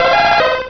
cubone.wav